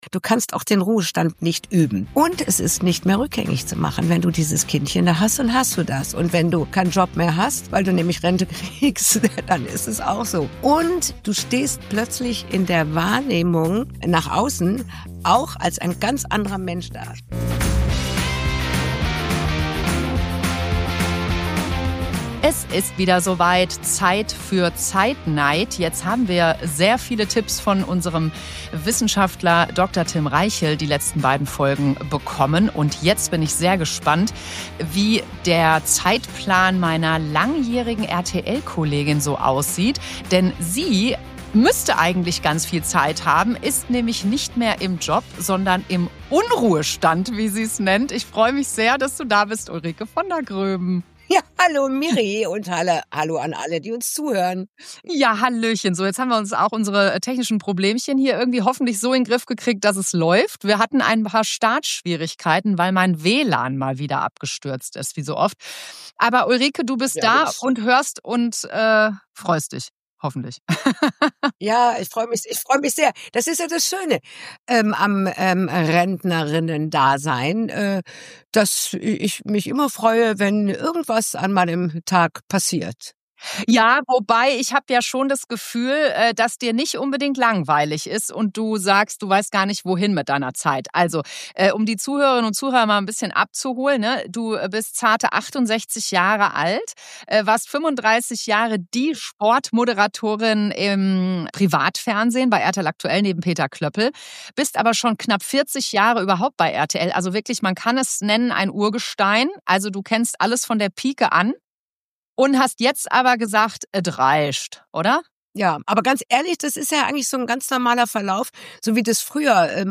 Ein Gespräch über Loslassen, Tempo rausnehmen und die Frage, wie man diesen Lebensabschnitt bewusst gestaltet.